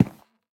Minecraft Version Minecraft Version latest Latest Release | Latest Snapshot latest / assets / minecraft / sounds / block / cherry_wood / step2.ogg Compare With Compare With Latest Release | Latest Snapshot
step2.ogg